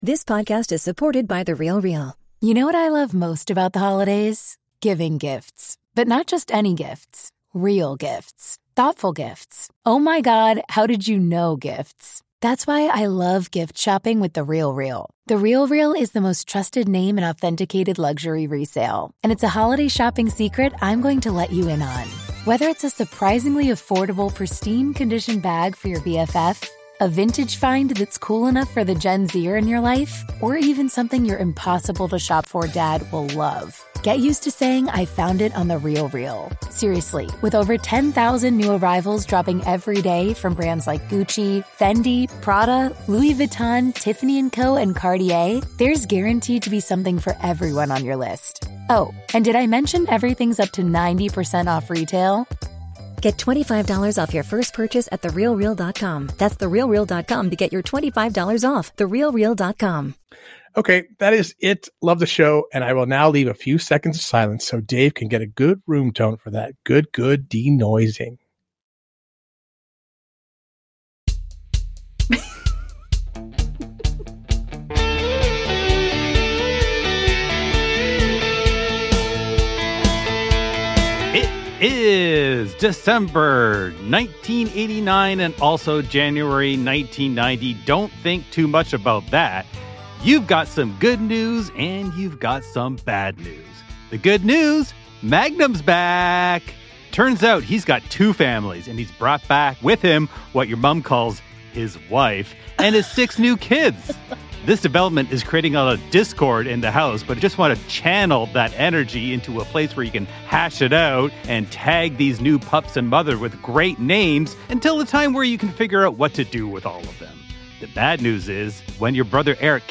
After the "Rate Your Ho-Ho-Ho-ness" quiz discussion takes a surprisingly contentious turn, we play some of your latest calls!